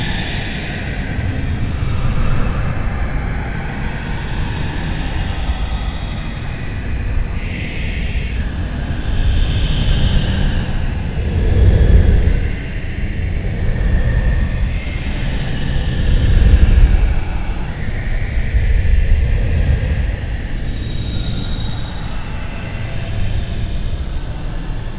assets/psp/nzportable/nzp/sounds/ambience/echoes.wav at 145f4da59132e10dabb747fa6c2e3042c62b68ff
echoes.wav